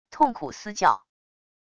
痛苦嘶叫wav音频